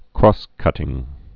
(krôskŭtĭng, krŏs-)